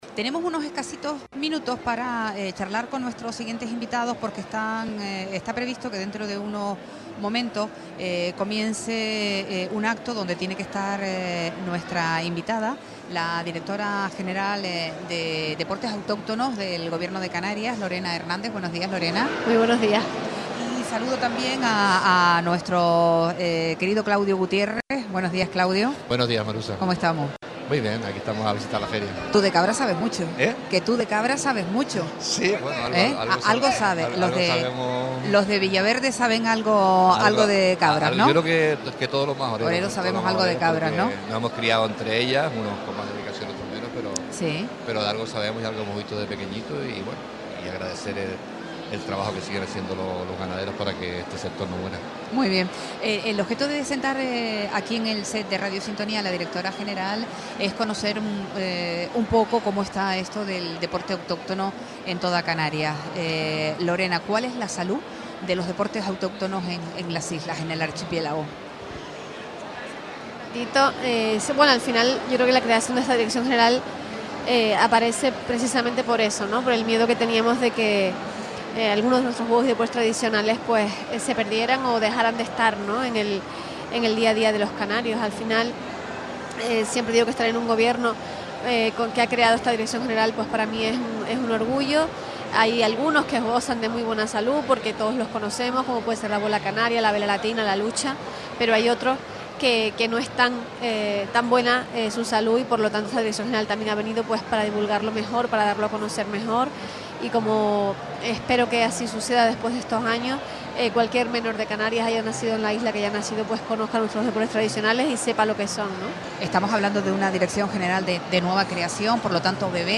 Entrevistas
La directora general de Deportes Autóctonos del Gobierno de Canarias, Lorena Hernández, participó en una entrevista en Radio Sintonía acompañada por el consejero del Partido Popular, Claudio Gutiérrez.